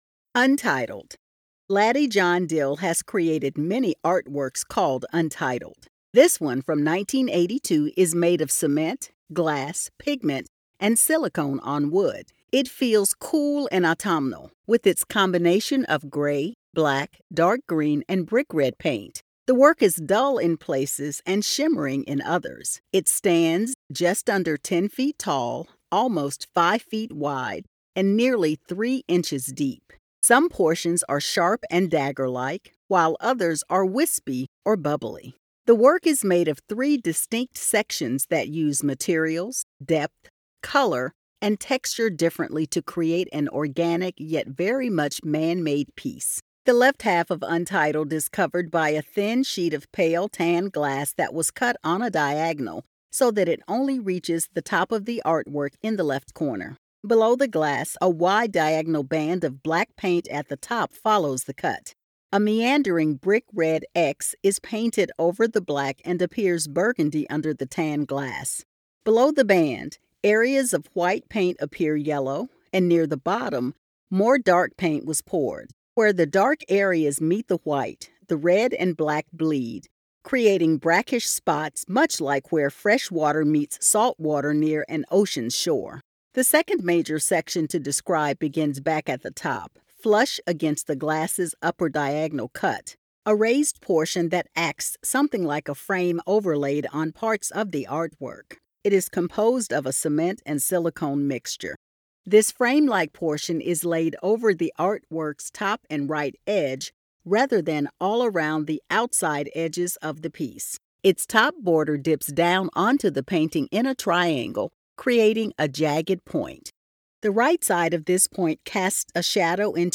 Audio Description (03:21)